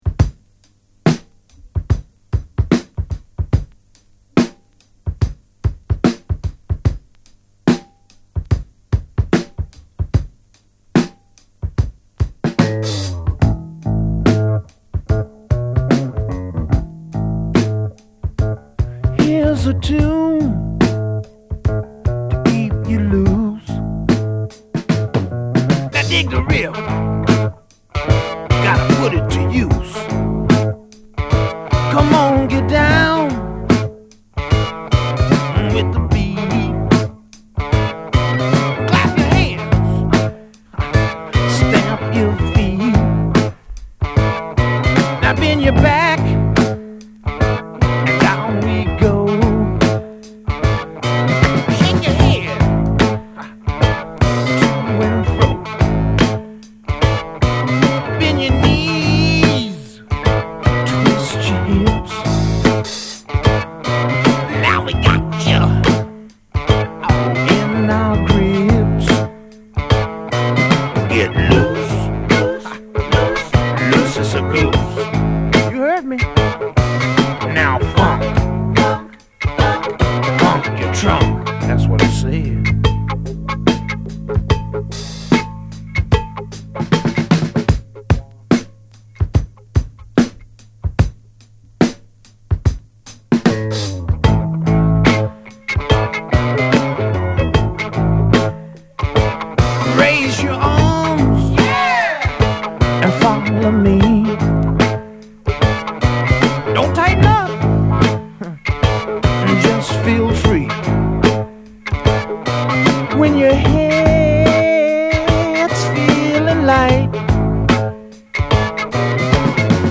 強力ブレイクを収録したUK産ロックの人気盤！